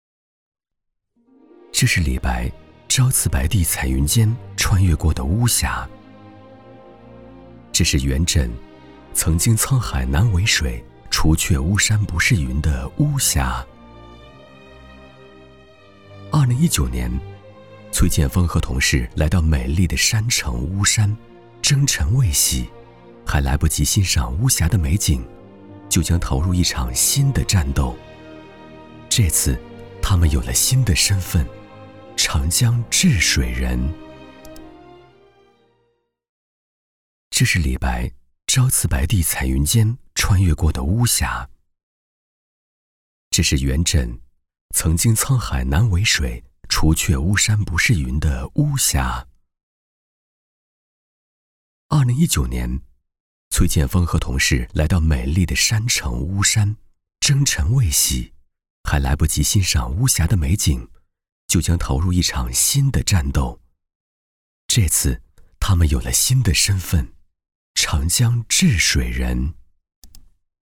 男国432_专题_人物_人物治水行者.mp3